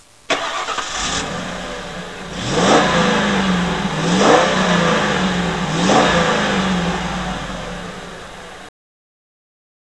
and listen to the grunt of the 5.4 Liter. No other engine on any Dodge or Chevy sounds that good.